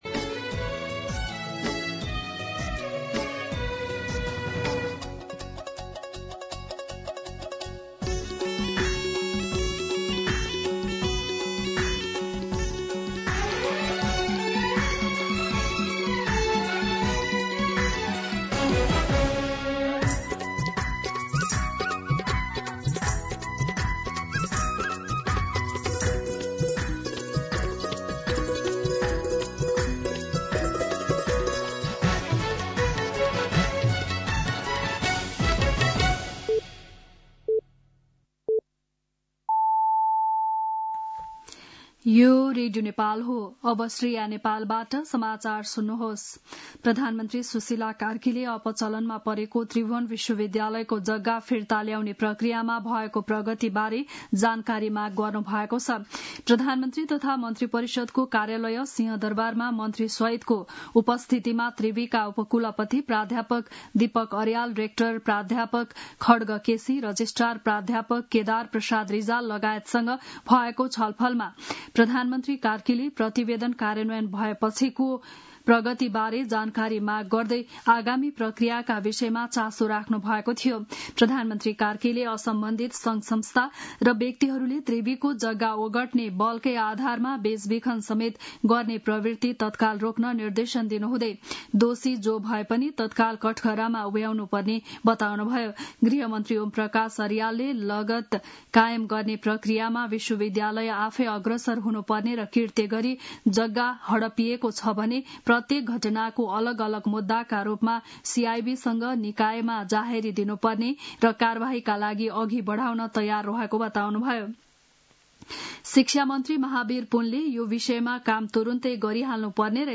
बिहान ११ बजेको नेपाली समाचार : २६ कार्तिक , २०८२